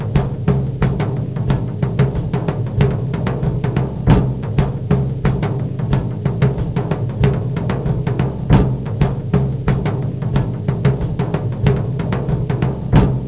The files are for speech plus noise, and only noise.
noise3.wav